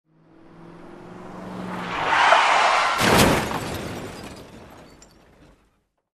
Реалистичные записи включают столкновения, скрежет металла, визг тормозов и другие эффекты.
Автомобильное ДТП на трассе